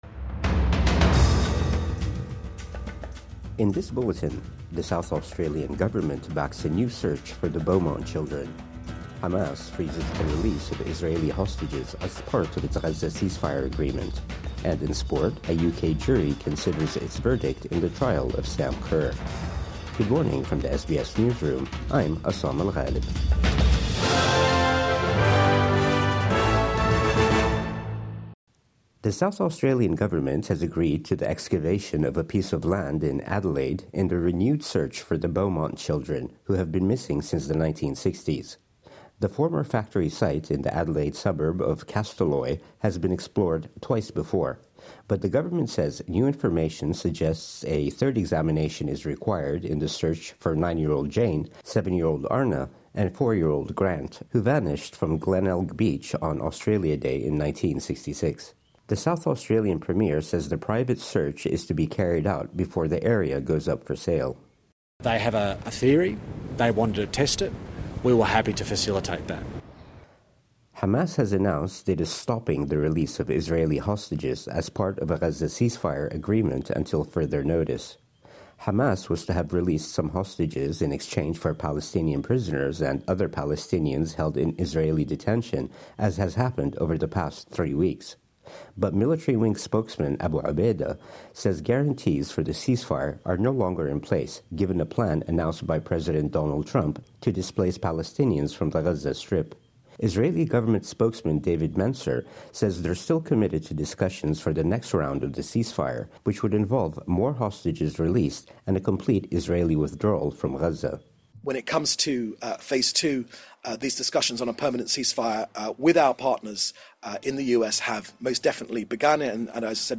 Hamas freezes the release of Israeli hostages | Morning News Bulletin 11 February 2025